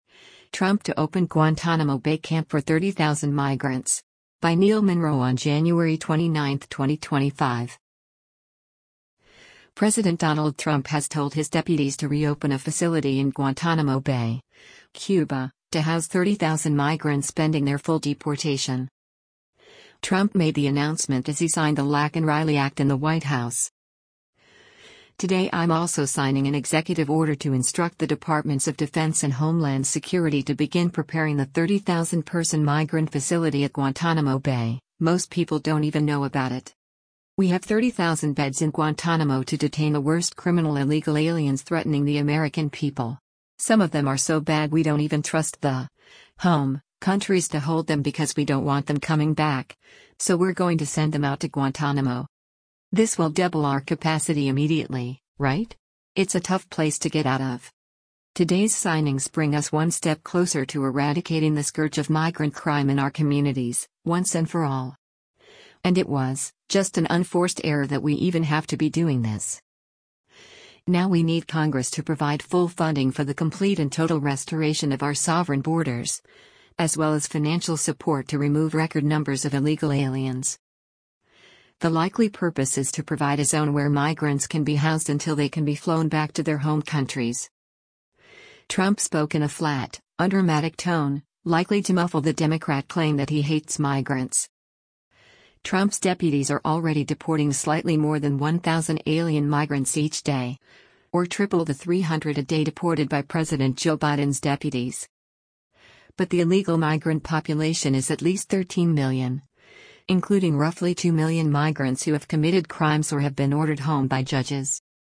Trump made the announcement as he signed the Laken Riley Act in the White House:
Trump spoke in a flat, undramatic tone, likely to muffle the Democrat claim that he hates migrants.